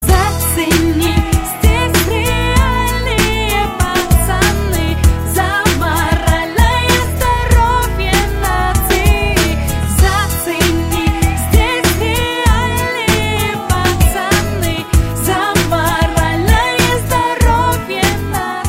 Kategorien Filmmusik